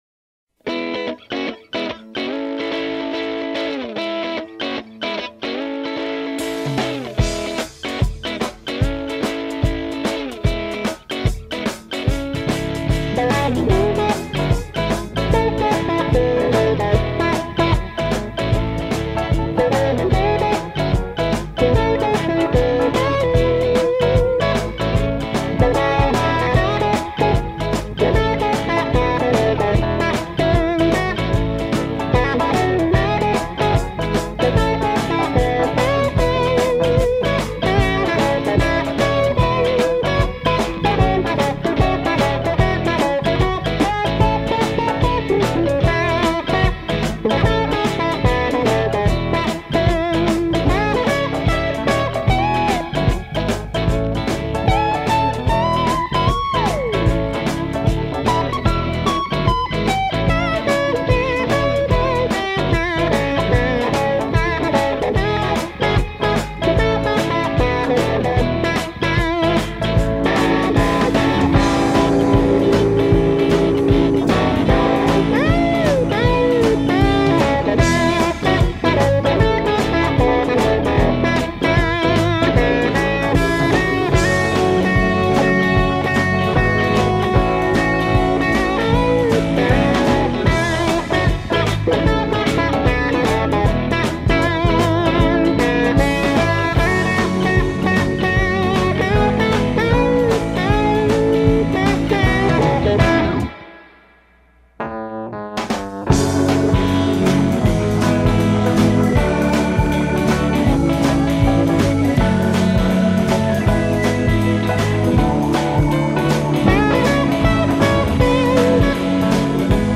blues/ funk rock superband
They make gospel music fun.